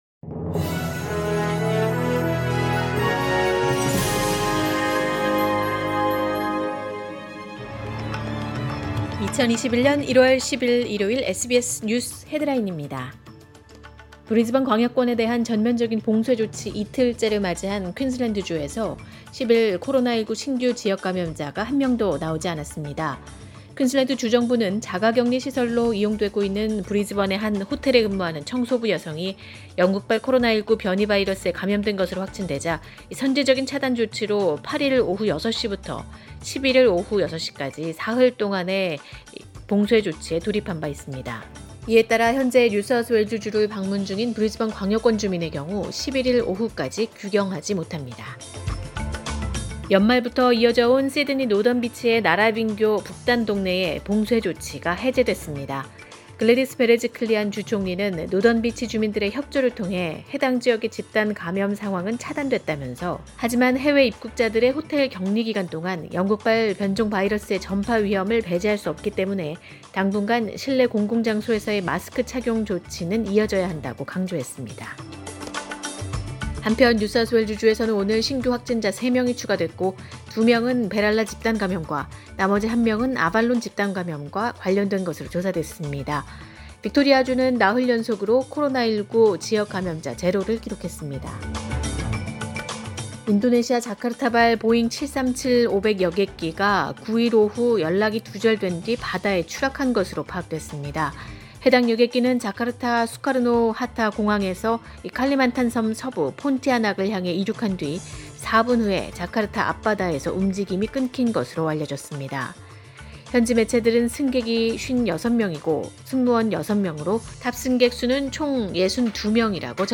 2021년 1월 10일 일요일 오전의 SBS 뉴스 헤드라인입니다.